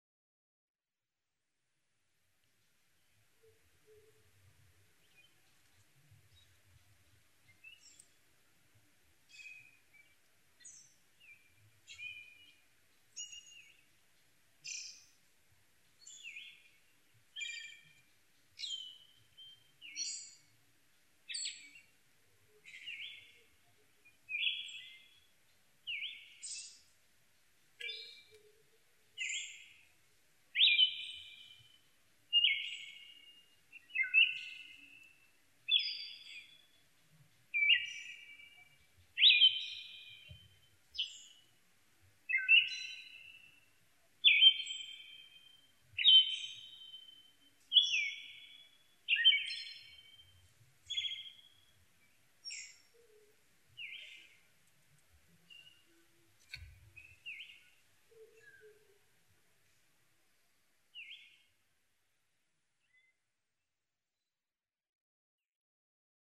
マミジロ　Turdus sibiricusツグミ科
日光市土呂部　alt=1240m
Mic: Panasonic WM-61A  Binaural Souce with Dummy Head
はじめに左方向から鳴き始め、別な個体が中央近くではき始めます。 他の自然音:　フクロウ